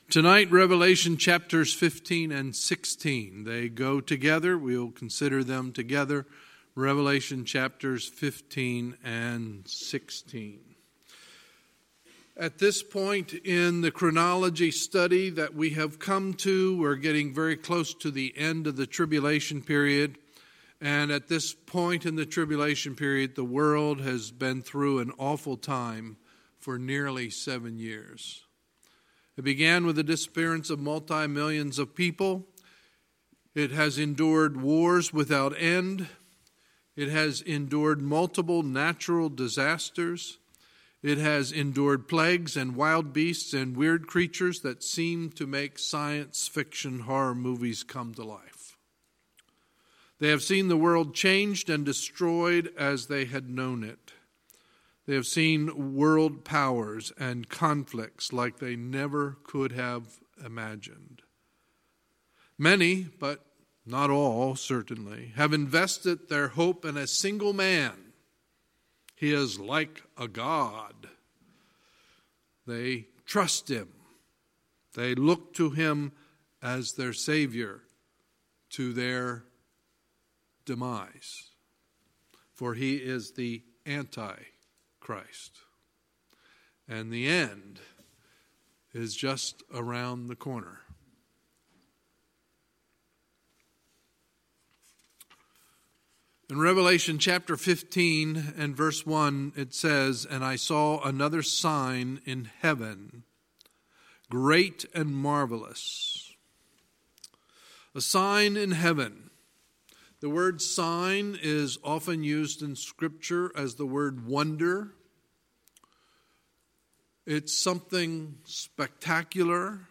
Sunday, May 5, 2019 – Sunday Evening Service